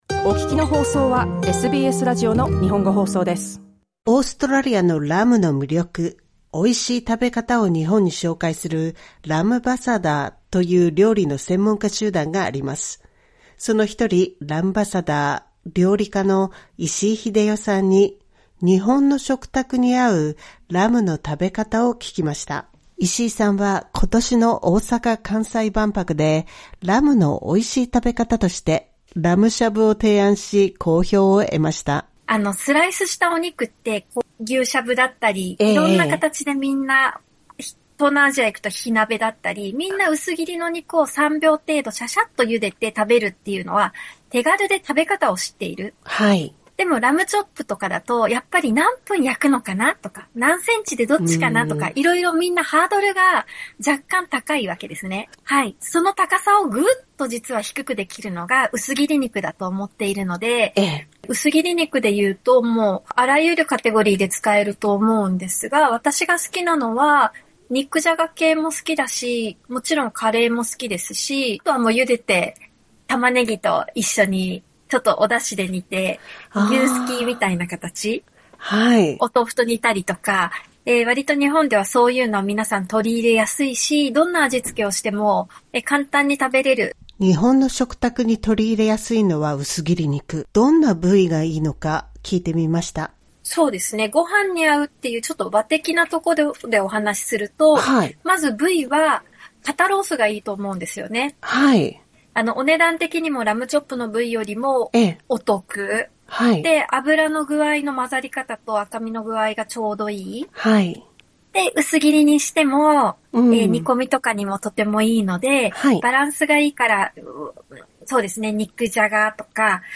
インタビュー番外編「ご飯に合うラムの料理」を聞きました。